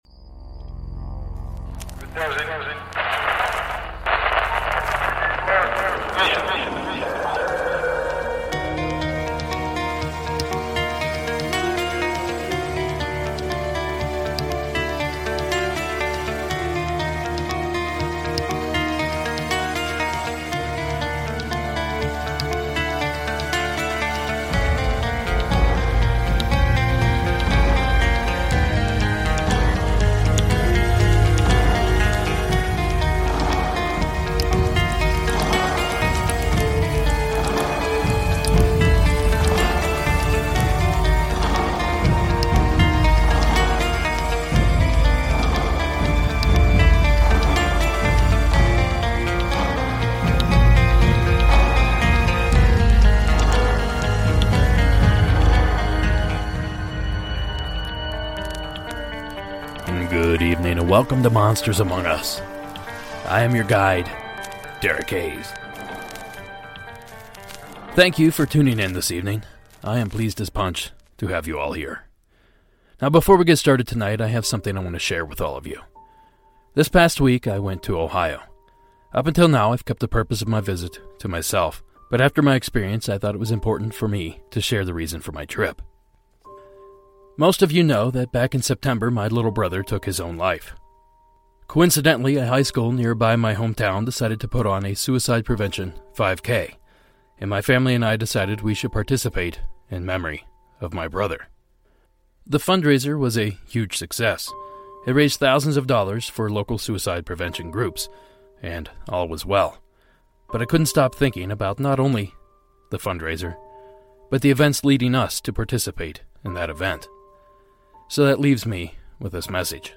Tonight we explore calls from all corners of the paranormal map. Bigfoot, shadowpeople, ufo's, ghosts and a strange being from over seas.